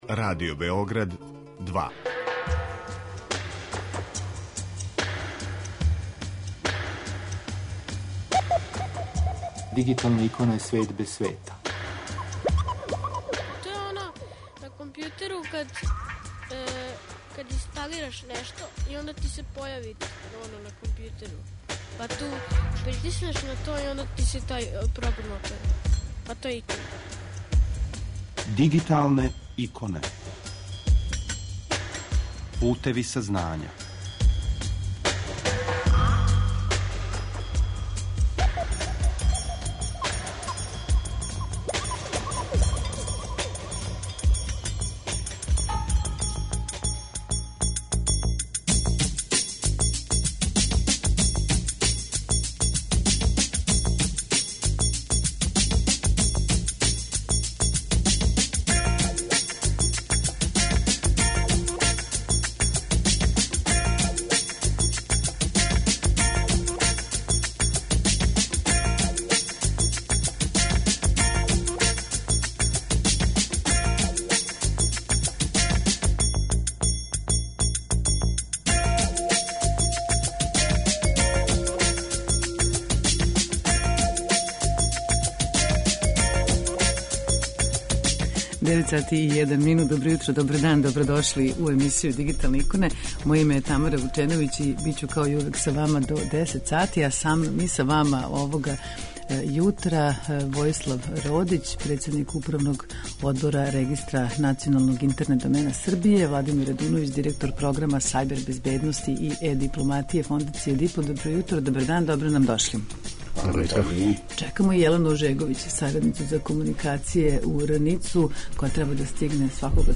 Са намa уживо на таласима Радио Београда 2